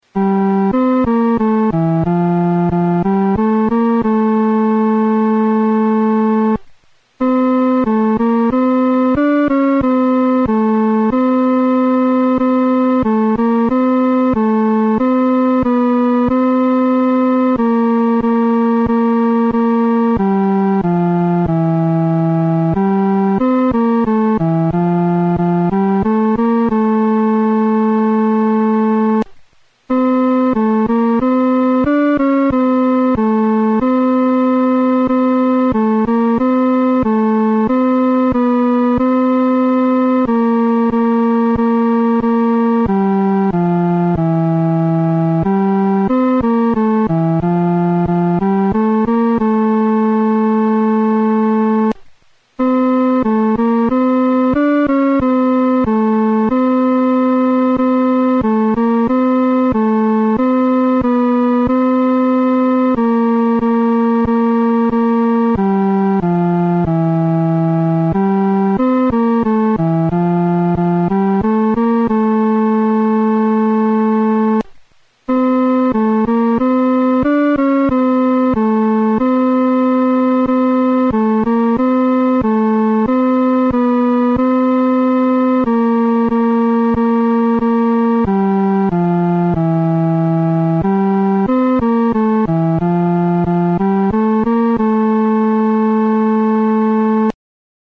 伴奏
女高
这首诗歌可用较流动的中速来弹唱。